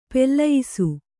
♪ pellayisu